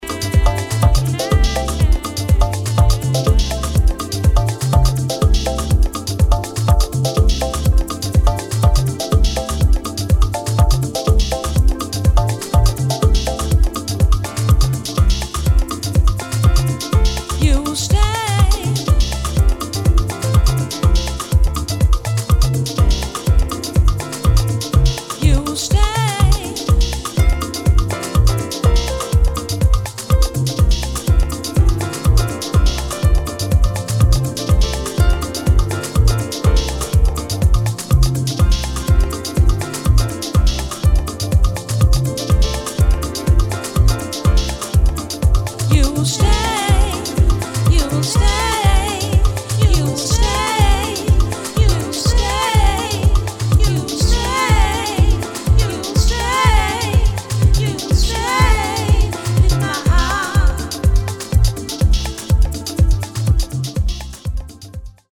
[ DEEP HOUSE ]